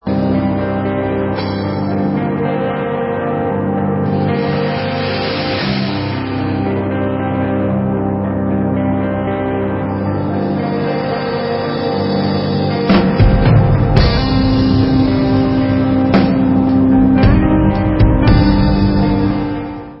Rock/Punk